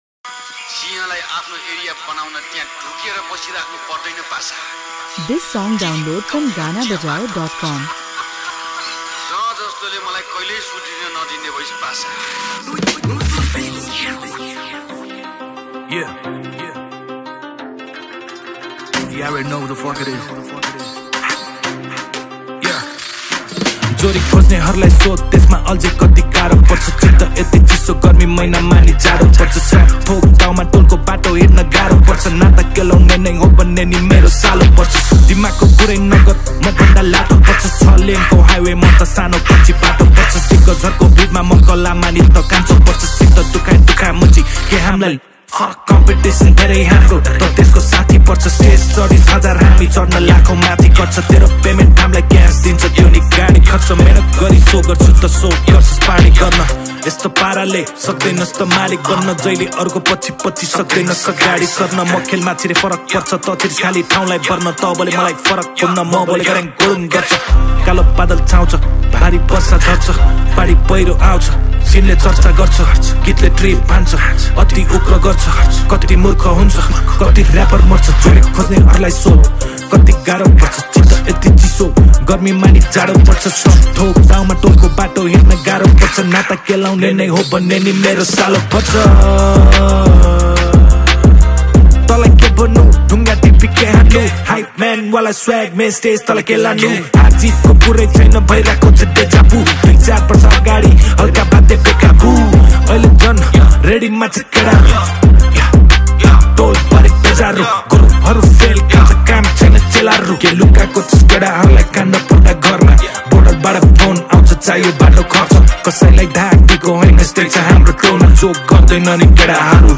# Nepali Hiphop Song